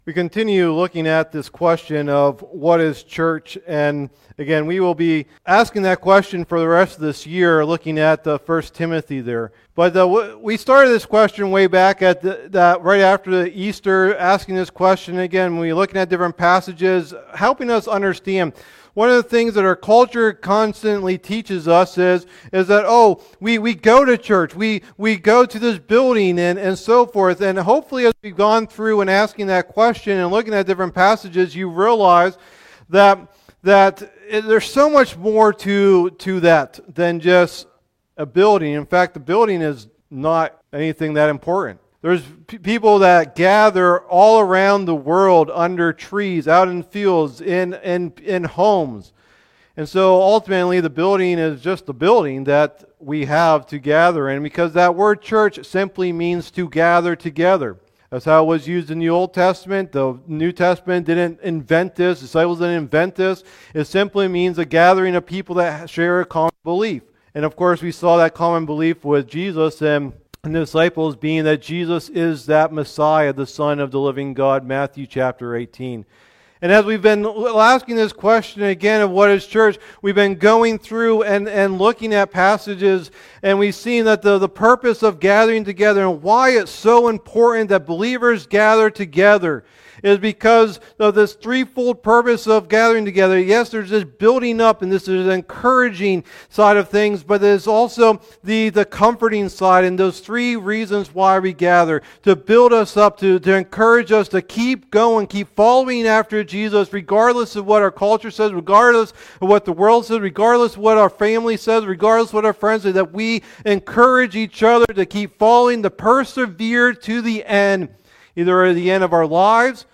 Message #19 of the "What is Church?" teaching series